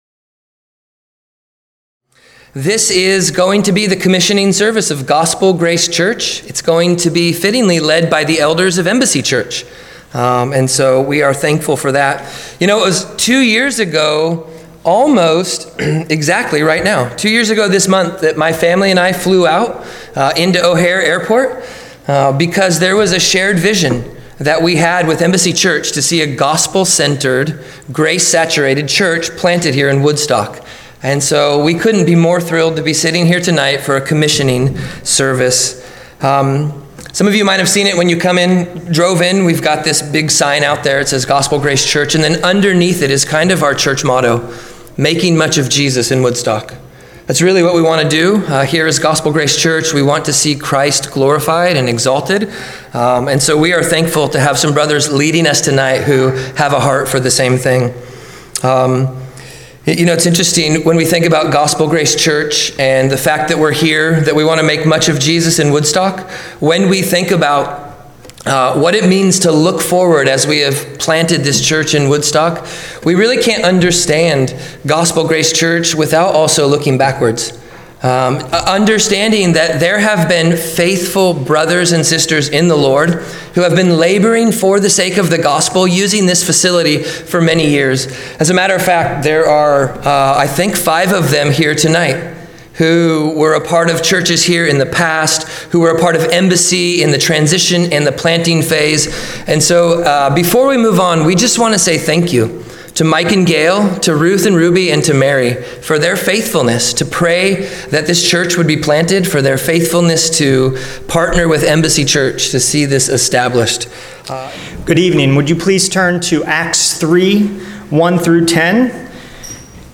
Commissioning-Service-Audio.mp3